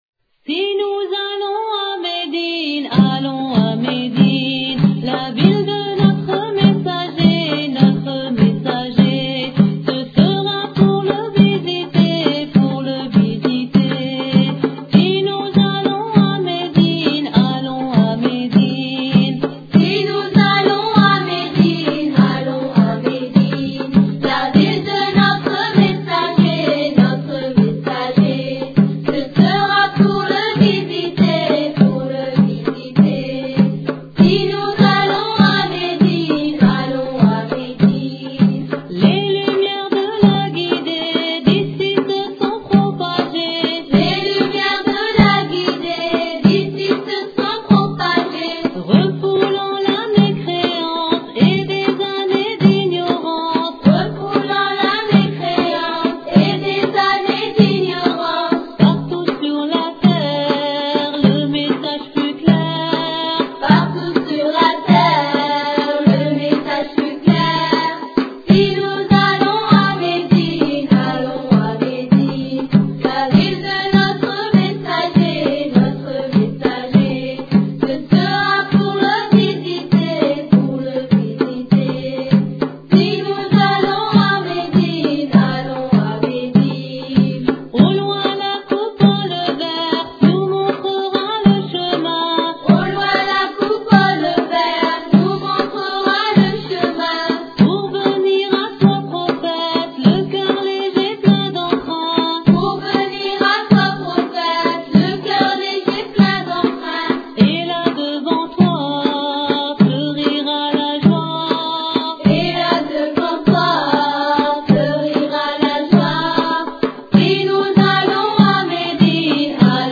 Chant réalisé par des soeurs
chants de soeurs spécial Aïd !